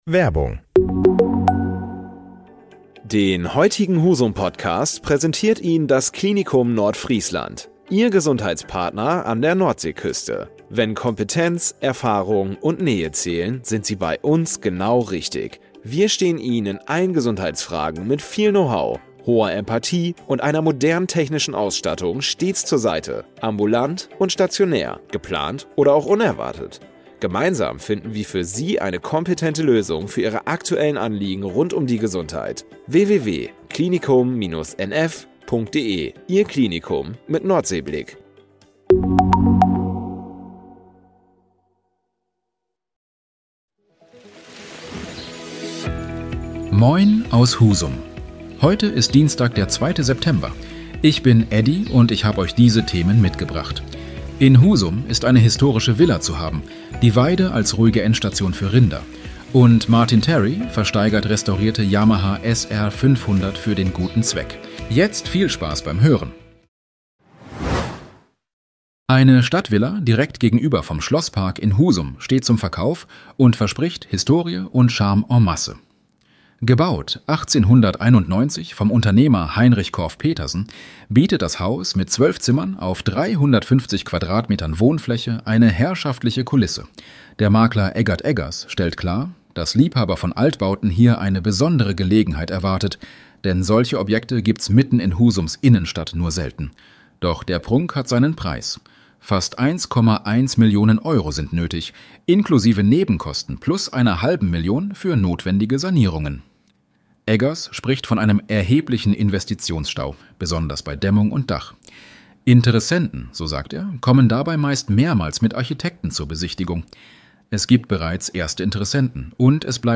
Deine täglichen Nachrichten
Nachrichten